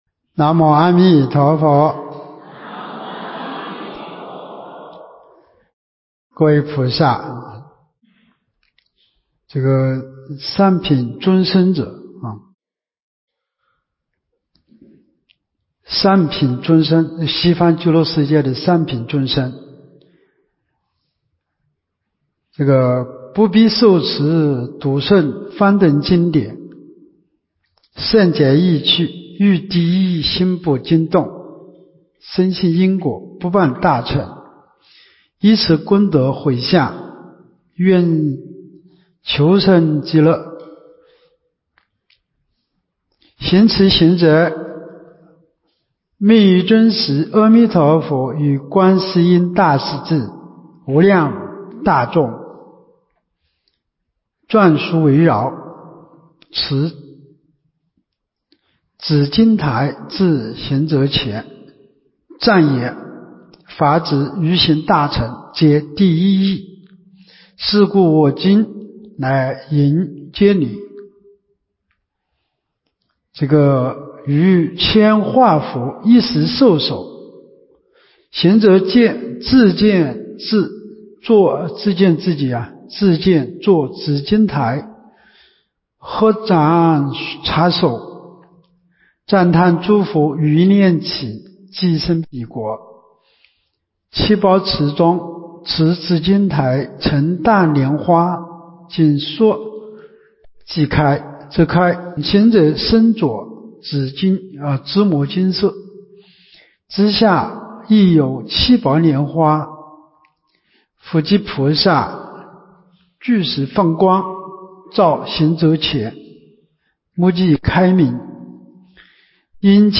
24年陆丰学佛苑冬季佛七（六）